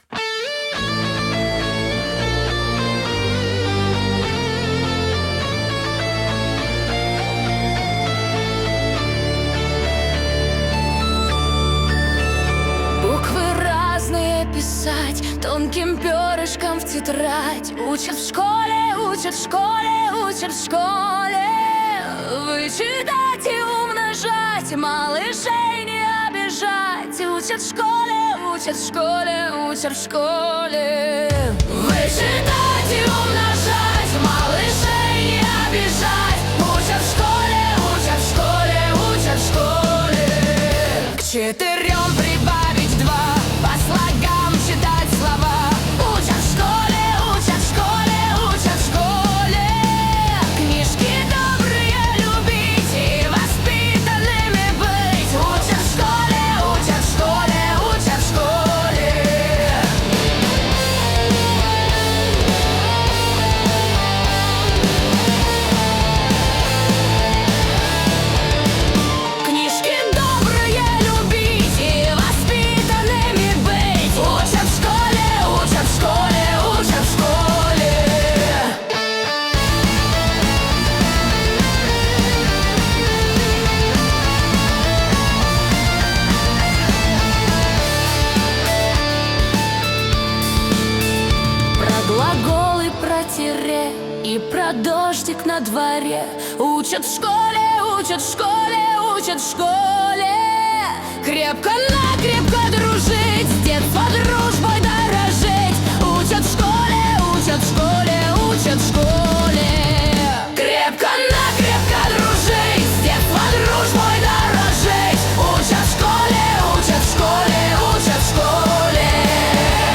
В Hard Rock Стиле Cover